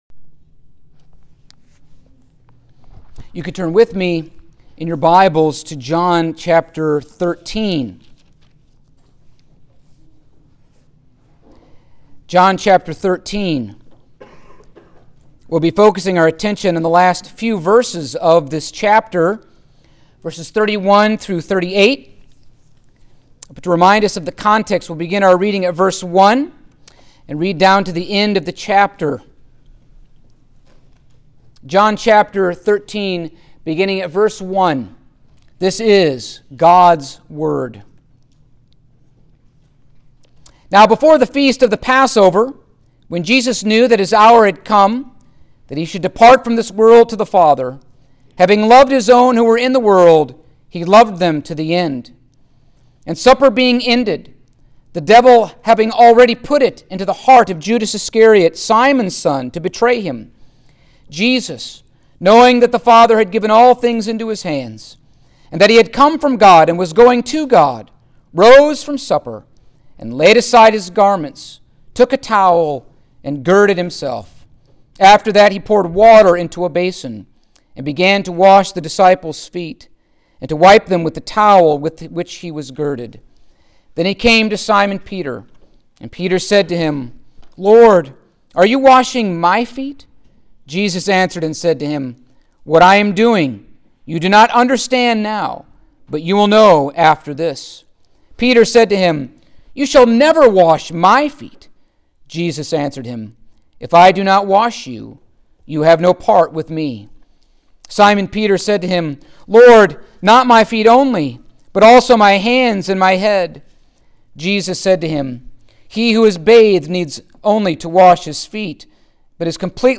Passage: John 13:31-38 Service Type: Sunday Morning